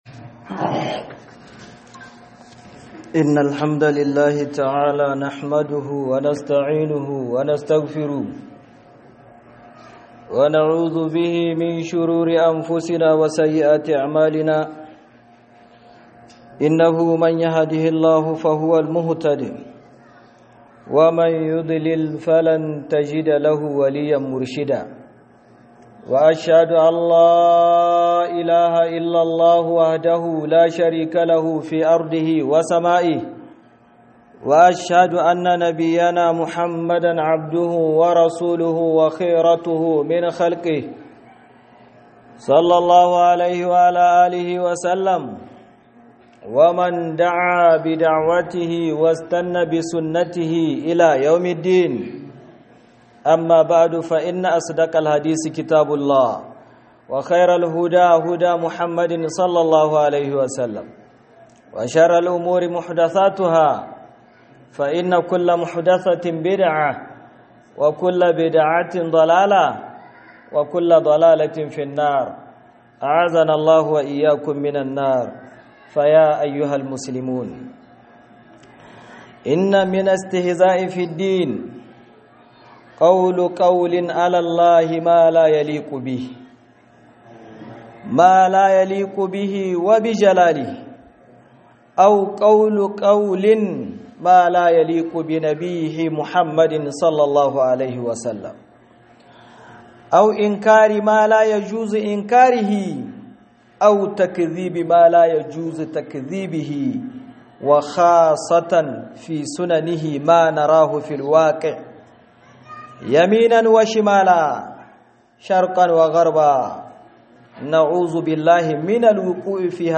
IZGILI GA ADDINI - HUDUBA